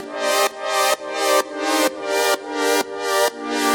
Index of /musicradar/french-house-chillout-samples/128bpm/Instruments
FHC_Pad A_128-C.wav